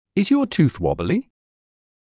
ｲｽﾞ ﾕｱ ﾄｩｰｽ ﾜｫﾌﾞﾘｰ